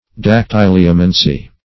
Search Result for " dactyliomancy" : The Collaborative International Dictionary of English v.0.48: Dactyliomancy \Dac*tyl"i*o*man`cy\, n. [Gr. dakty`lios + -mancy.] Divination by means of finger rings.